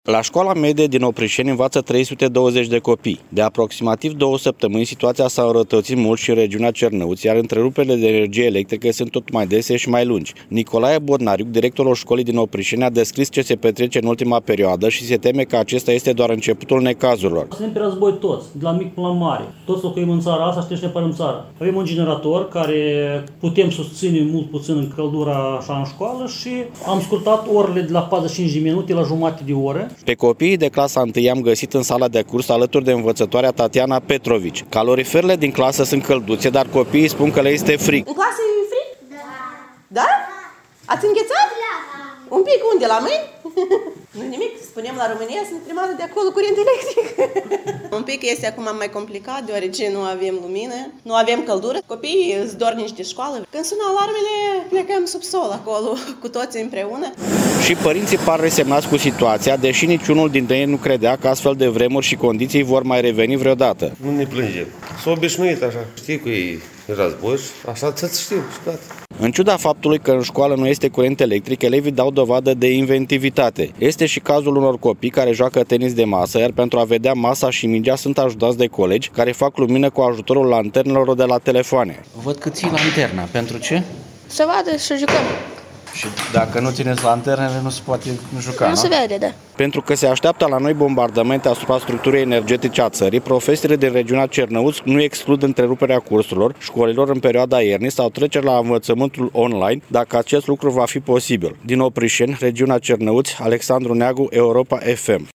Reportaj: Cum sunt resimțite la Cernăuți penele de curent.